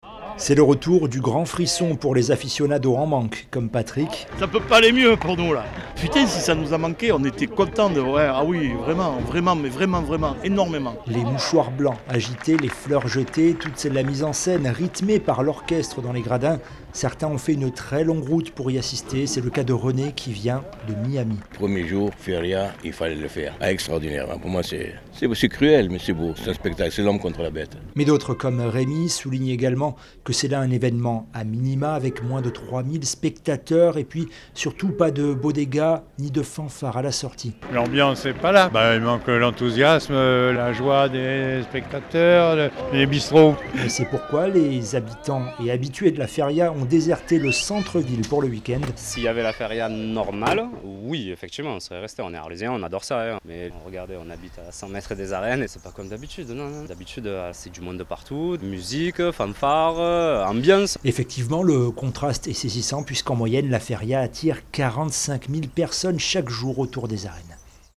Reportage
Les mouchoirs blancs agités, les fleurs jetées... toute la mise en scène est rythmée par l'orchestre dans les gradins.